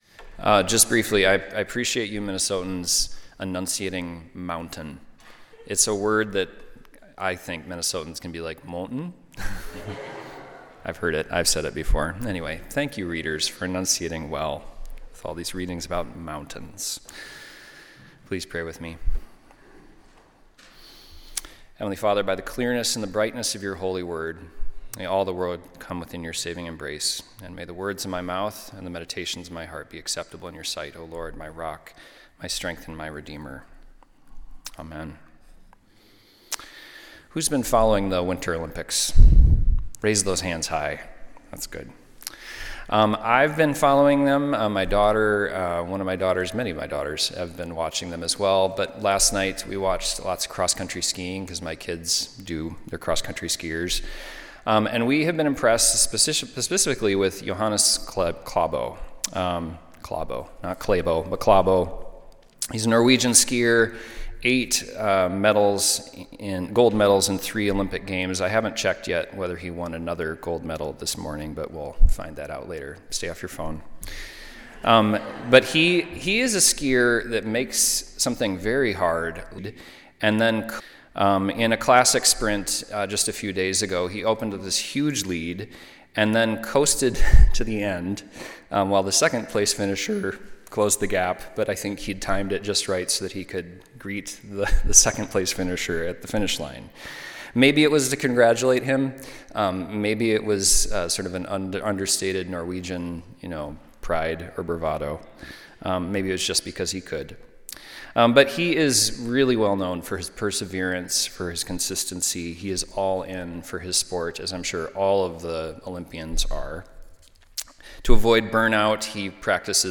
Sunday Worship–February 15, 2026
Sermons